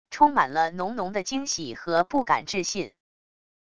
充满了浓浓的惊喜和不敢置信wav音频